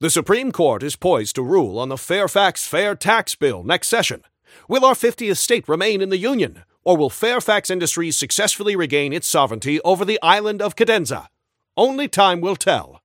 [[Category:Newscaster voicelines]]
Newscaster_headline_78.mp3